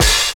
60 OP HAT.wav